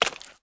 STEPS Swamp, Walk 28.wav